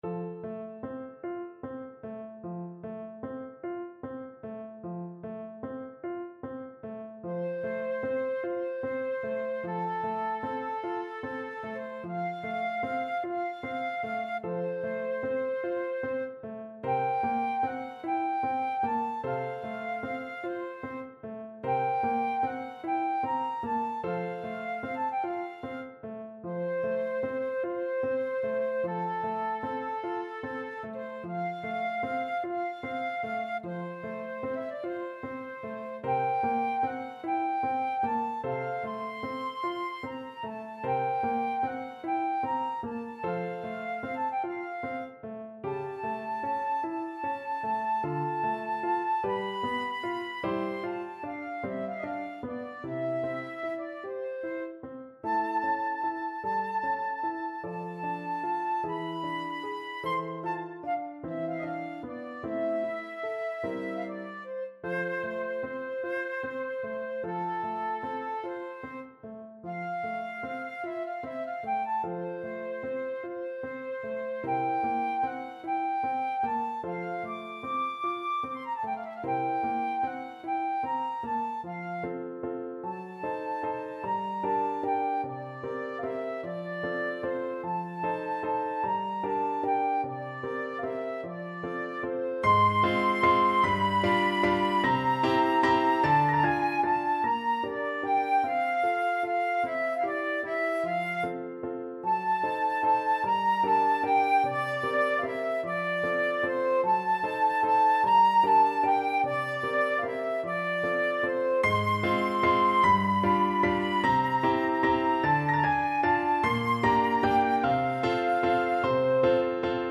Largo
Classical (View more Classical Flute Music)